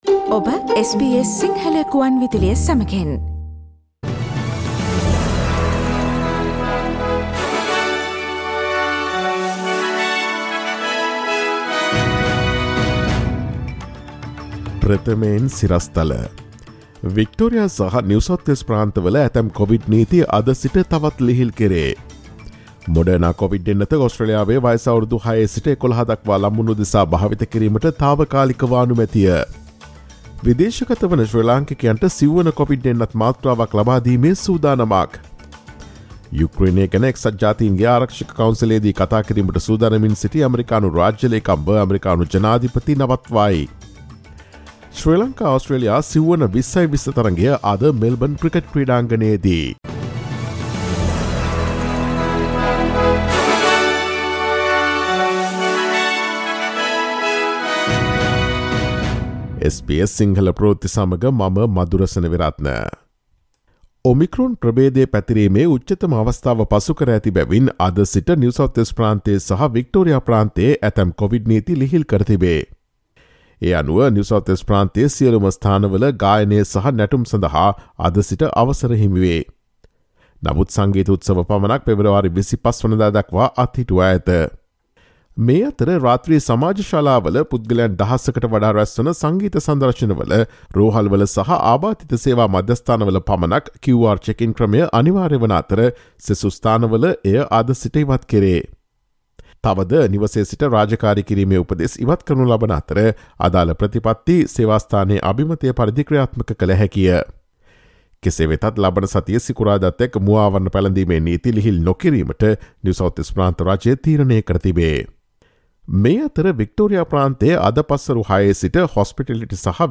ඔස්ට්‍රේලියාවේ සහ ශ්‍රී ලංකාවේ නවතම පුවත් මෙන්ම විදෙස් පුවත් සහ ක්‍රීඩා පුවත් රැගත් SBS සිංහල සේවයේ 2022 පෙබරවාරි 18 වන දා සිකුරාදා වැඩසටහනේ ප්‍රවෘත්ති ප්‍රකාශයට සවන් දීමට ඉහත ඡායාරූපය මත ඇති speaker සලකුණ මත click කරන්න.